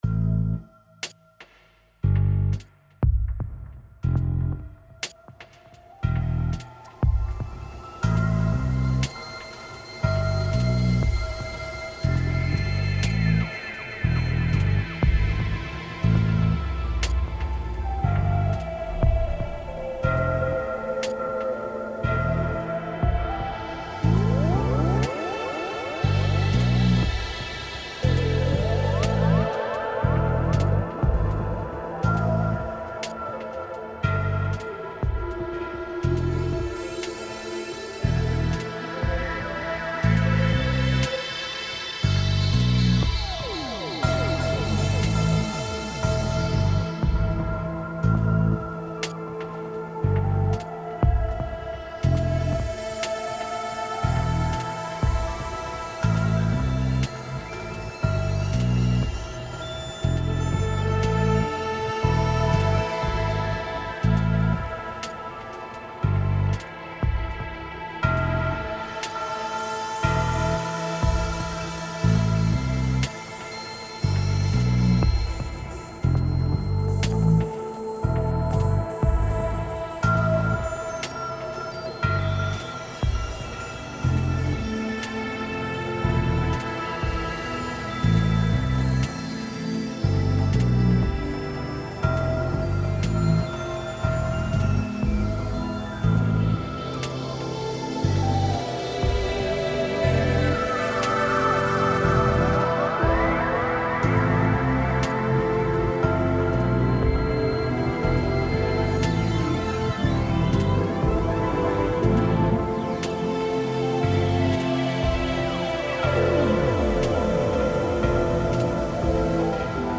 Even taking the digital synthesizers to their limits can neither explain nor convey what tinnitus really is like.